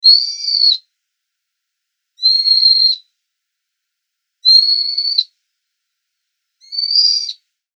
ヒヨドリ2.mp3